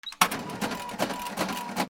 レジ 開く
/ M｜他分類 / L10 ｜電化製品・機械